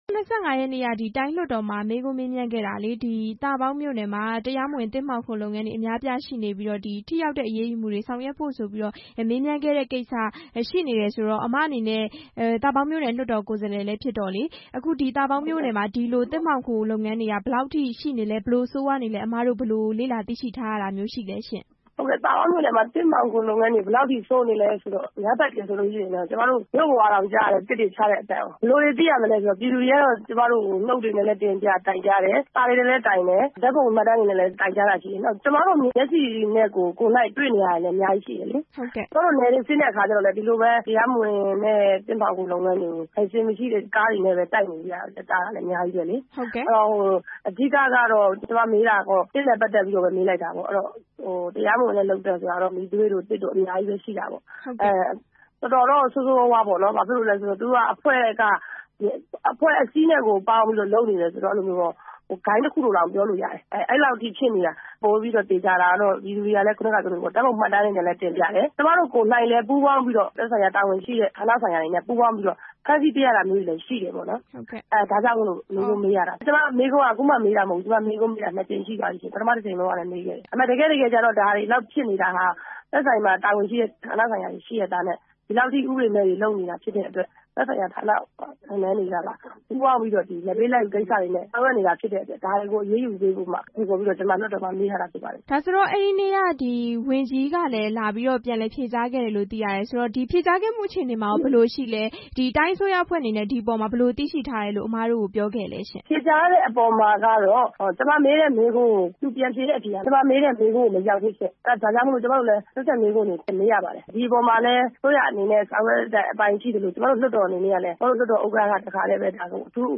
သာပေါင်းမြို့နယ်က သစ်မှောင်ခိုလုပ်ငန်းတွေအကြောင်း မေးမြန်းချက်